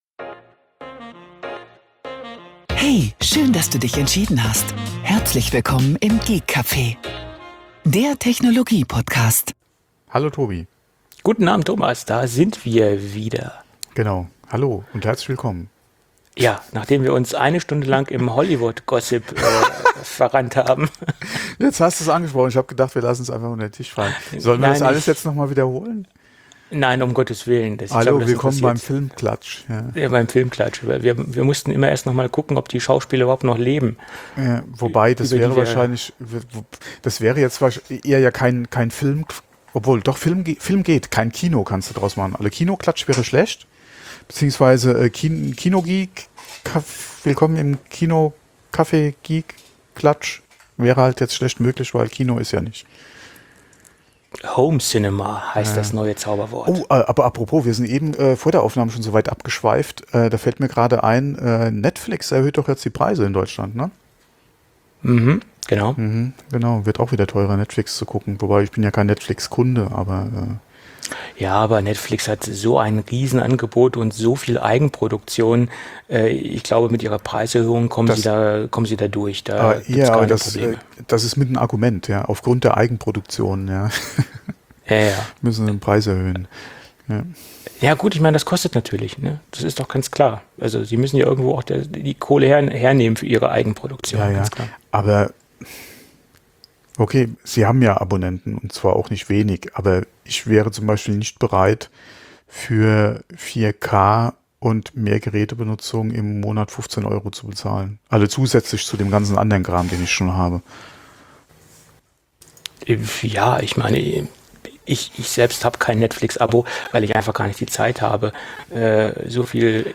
Über 7 Jahre veröffentlichten wir so insgesamt über 320 Folgen mit Gadget Reviews, App-Tipps, den neusten News aus der Apfelwelt und etliche Sonderfolgen. Die lockere Atmosphäre während der Aufnahme ist dem Konzept – oder eben dem Fehlen desselbigen zu verdanken.